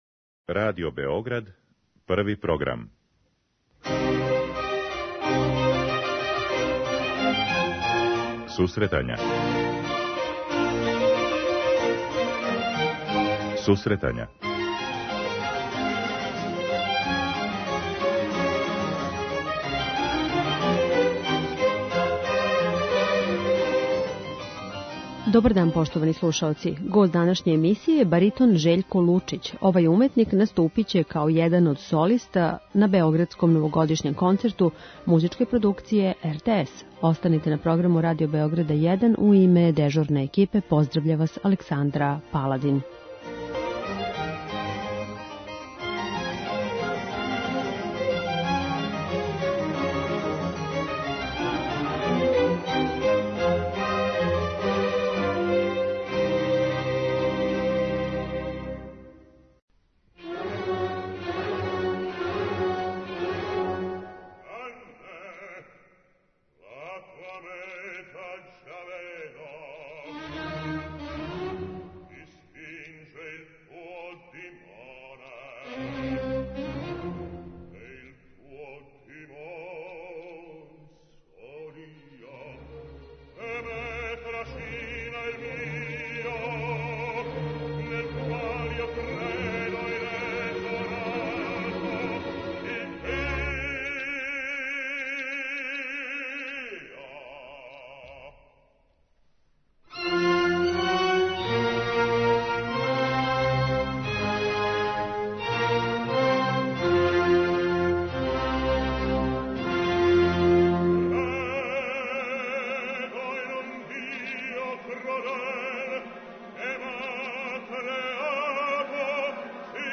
Жељко Лучић, баритон